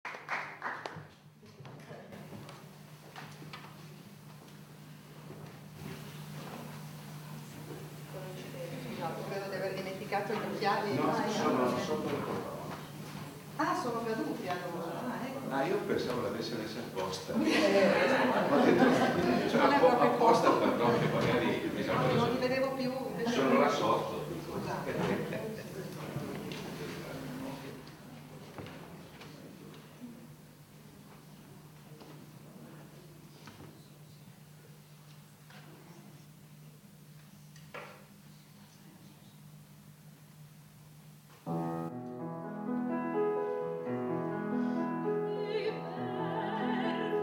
Concerto "Sulle ali del canto"